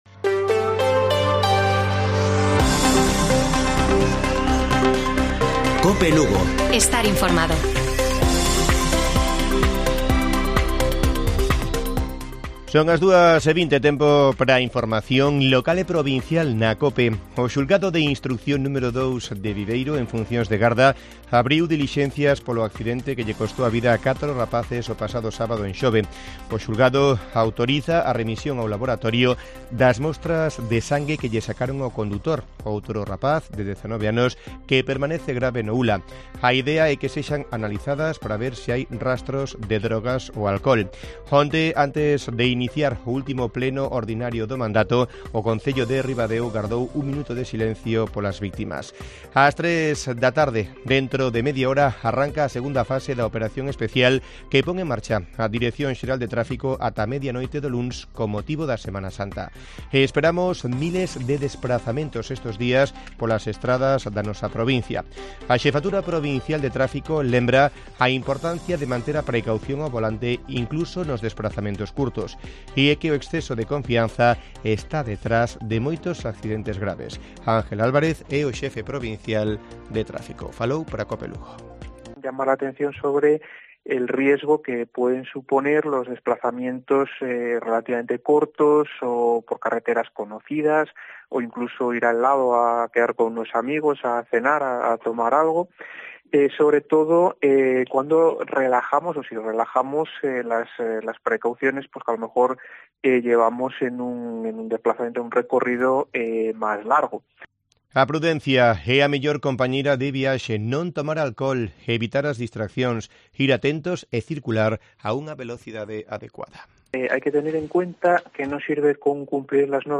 Informativo Mediodía de Cope Lugo. 5 de abril. 14:20 horas